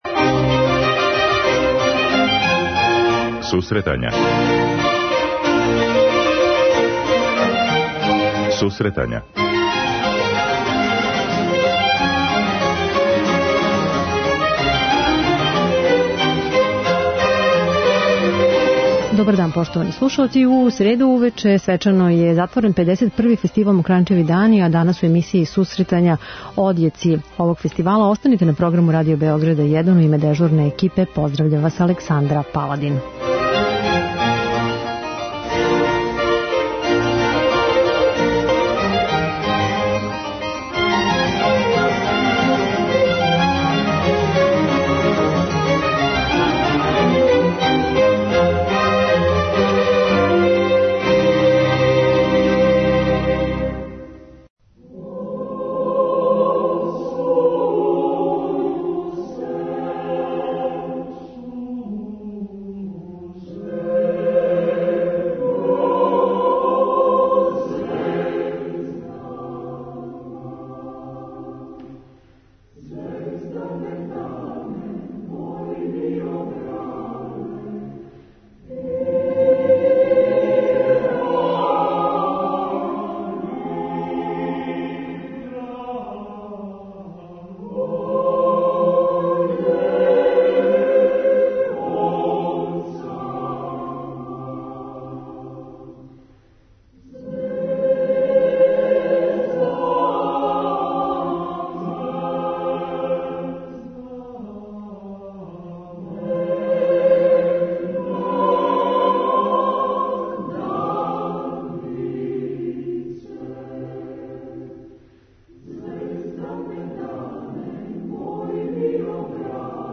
преузми : 9.88 MB Сусретања Autor: Музичка редакција Емисија за оне који воле уметничку музику.